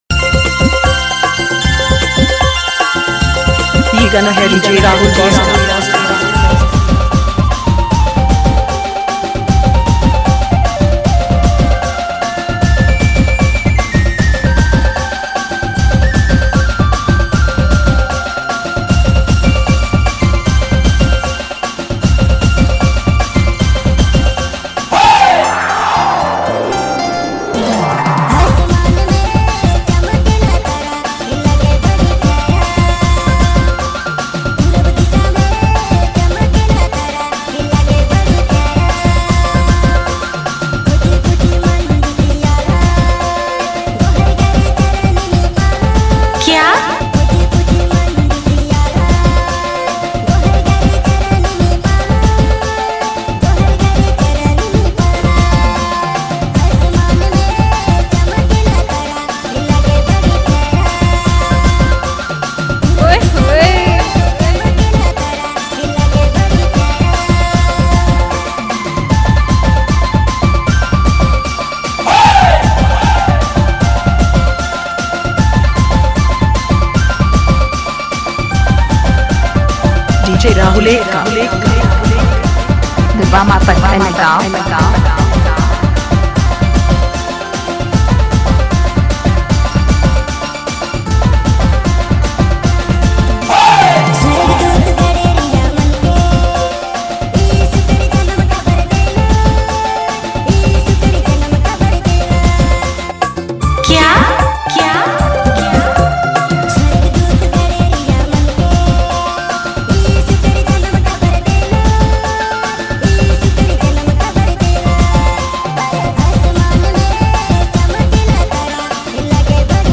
Dj Remixer
Christmas Dhanka Mix Song